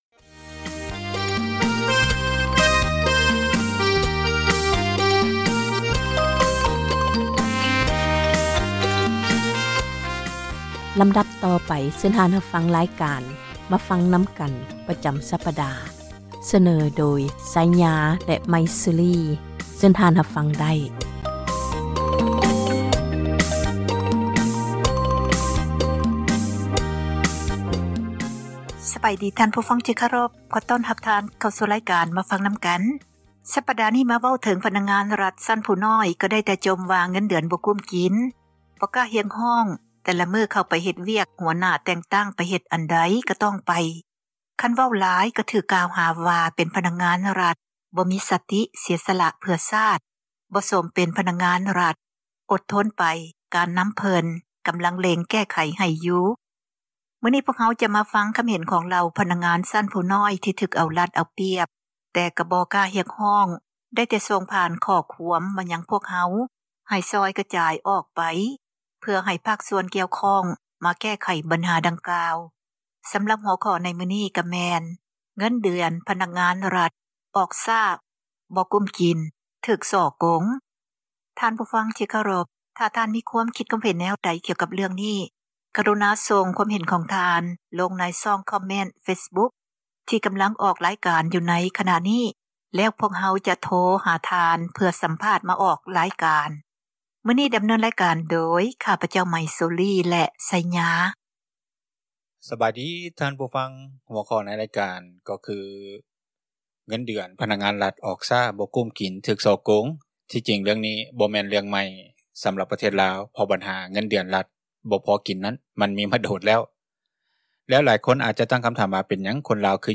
ທ່ານມີ ຄວາມຄຶດຄວາມເຫັນຫຍັງ ກໍສົ່ງຄອມເມັ້ນ ມາຍັງເຟສບຸກຄ໌ ຂອງພວກເຮົາ ທີ່ກຳລັງອອກ ຣາຍການ ໃນຕອນນີ້, ແລ້ວພວກເຮົາ ຈະໂທຫາ ສັມພາດ ມາອອກຣາຍການ.